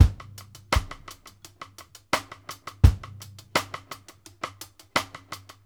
Index of /90_sSampleCDs/Sampleheads - New York City Drumworks VOL-1/Partition F/SP REGGAE 84
HAT+SNARE -L.wav